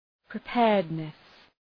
{prı’peərıdnıs}
preparedness.mp3